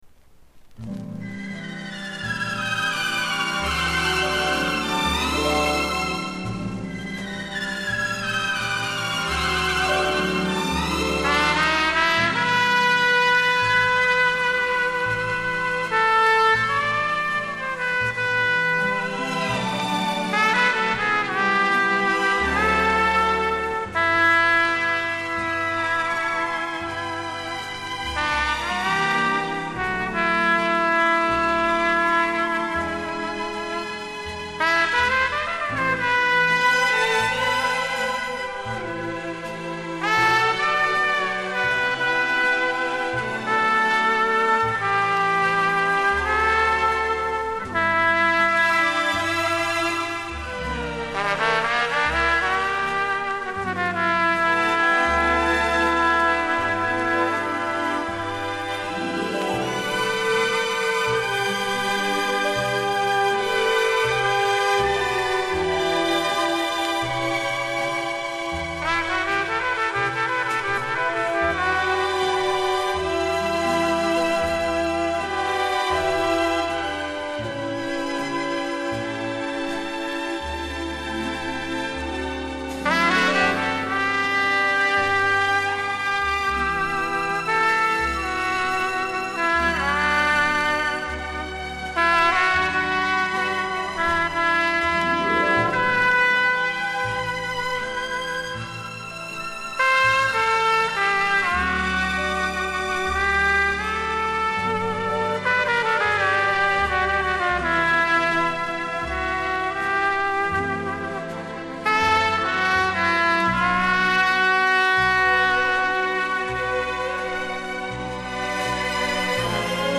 в стиле "ballada" со струнным оркестром